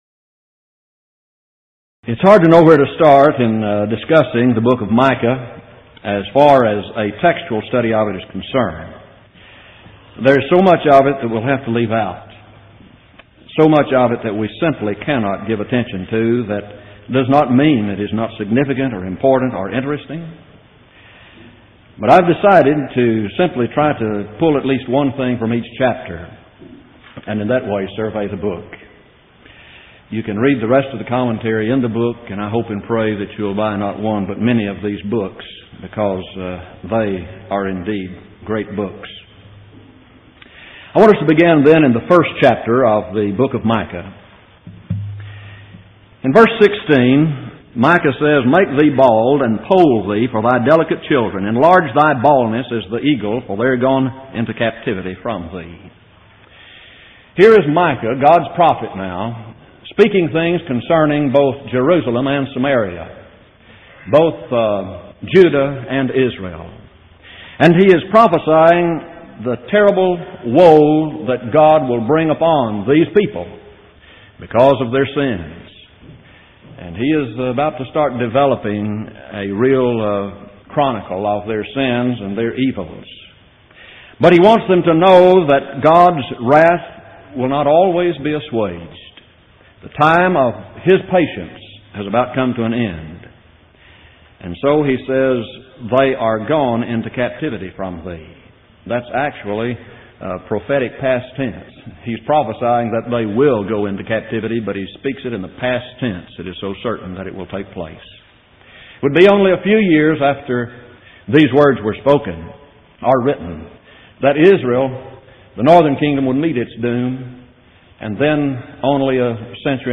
Event: 1990 Power Lectures
lecture